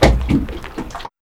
2504L WATER.wav